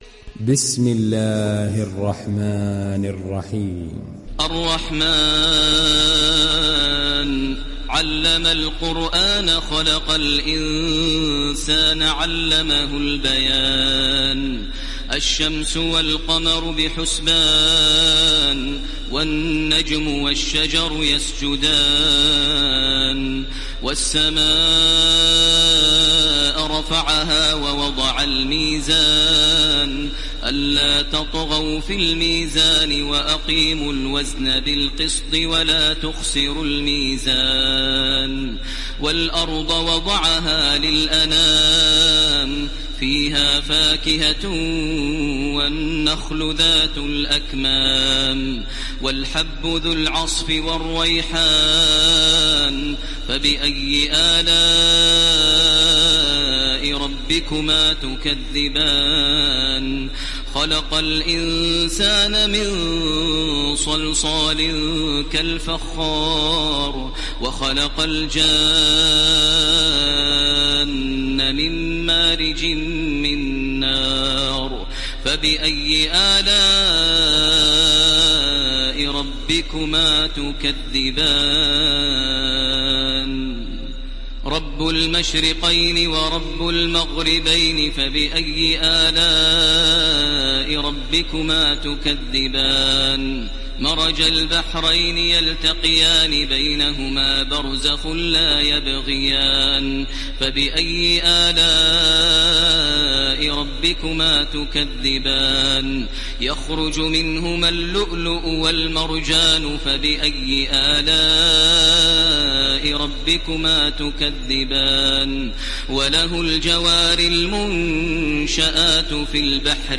Download Surah Ar Rahman Taraweeh Makkah 1430